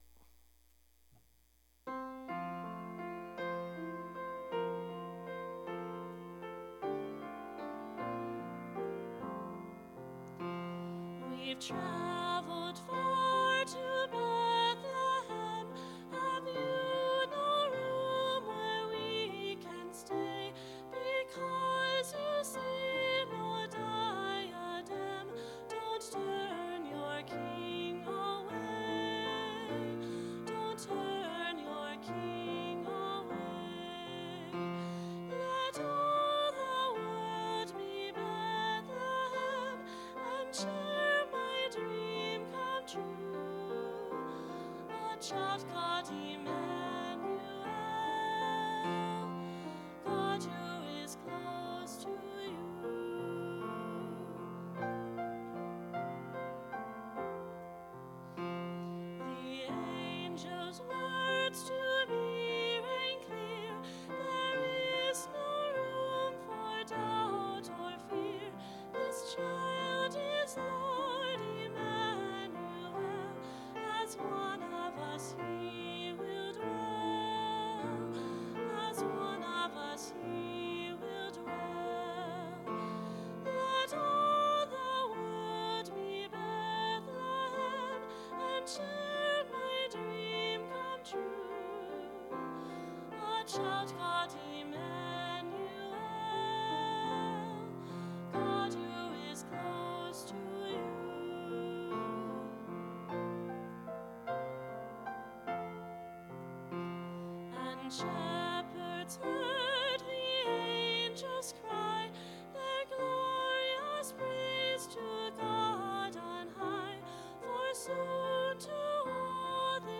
Click here for practice track